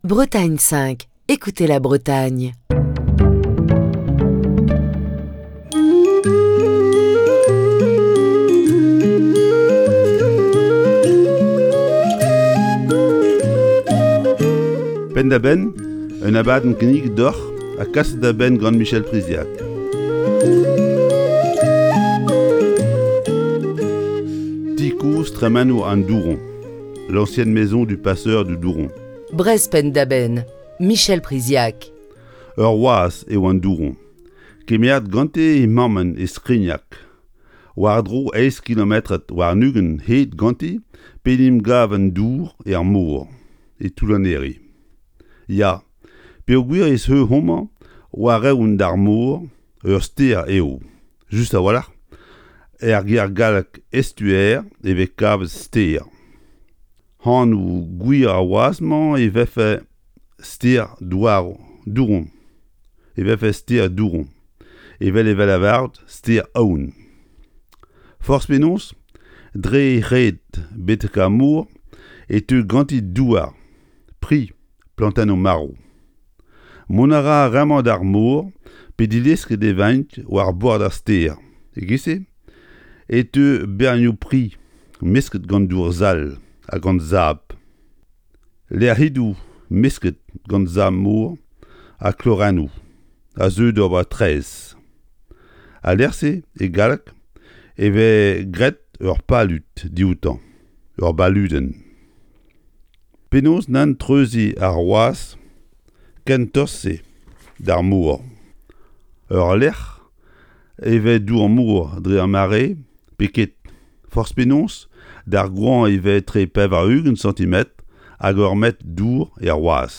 Chronique du 11 mars 2022.